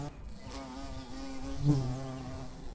bees.1.ogg